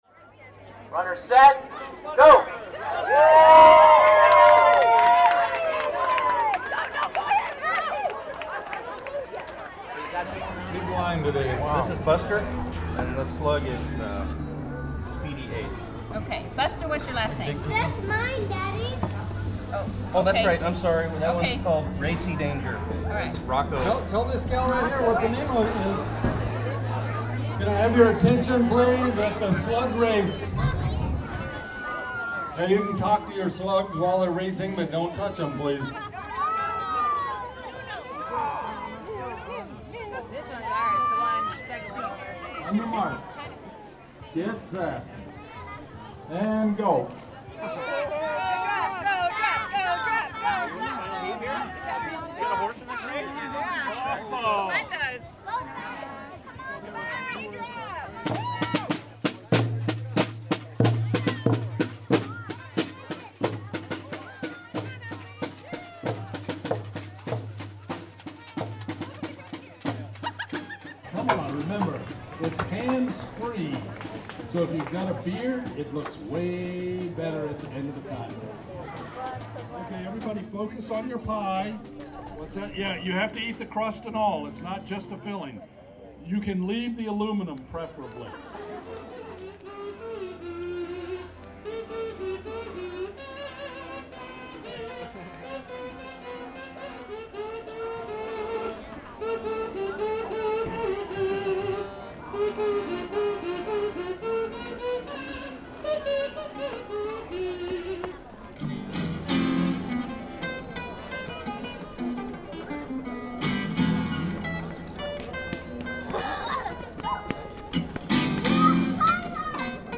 Competitions continued throughout the day with a pie eating contest, talent show and other festivities such as a homemade instrument parade. KRBD has this audio postcard from Main Street.